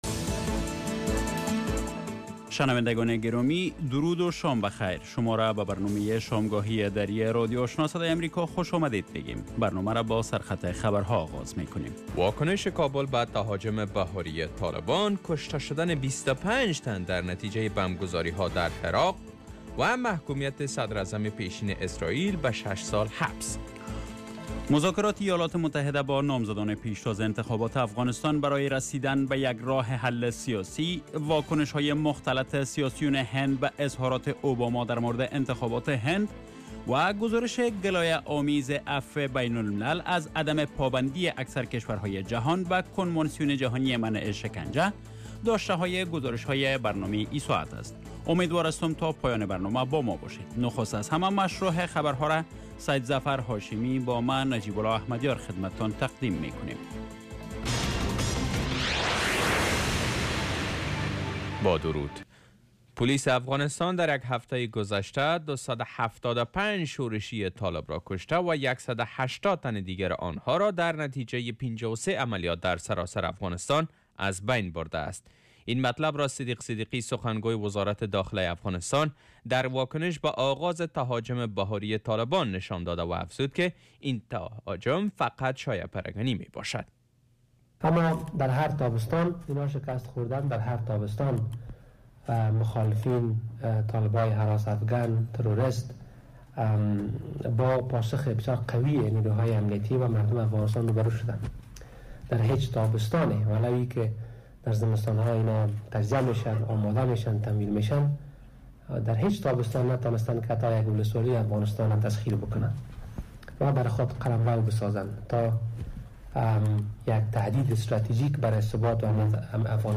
Radio evening first news half-hour show